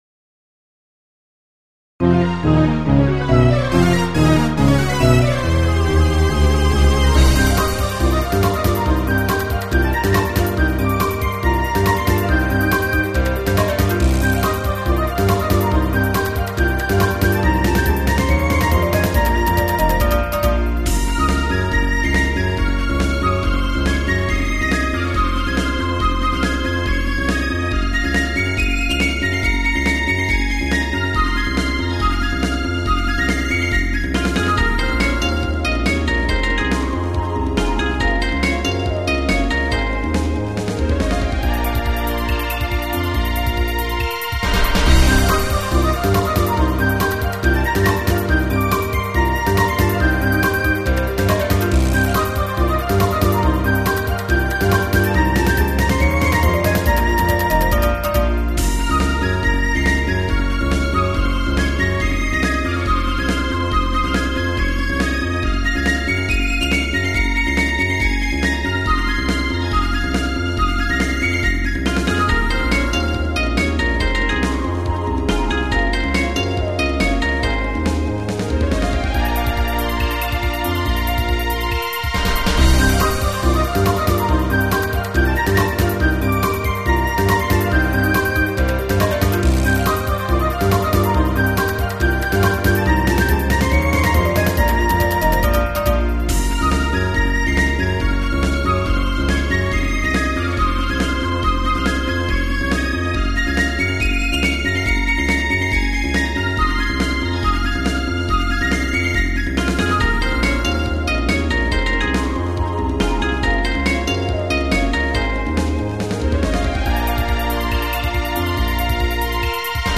アップロードサイズ制限の都合上mp3の劣化が激しいのはご愛嬌
耳コピ